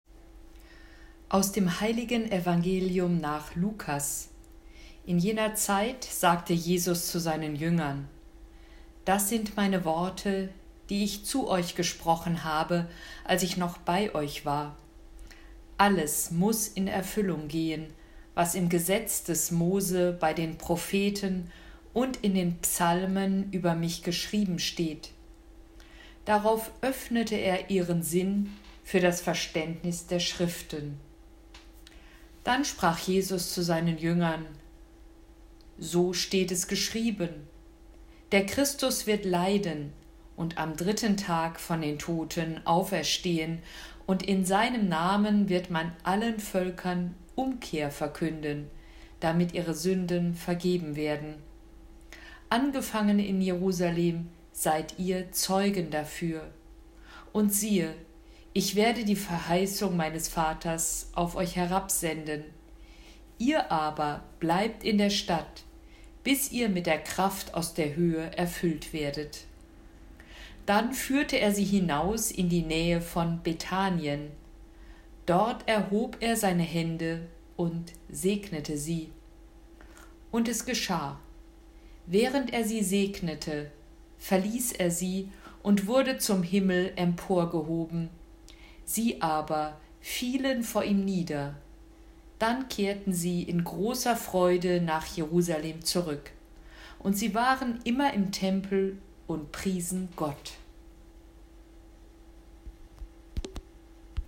Sprechtraining
Audio-Datei zum Üben der Aussprache von liturgischen Texten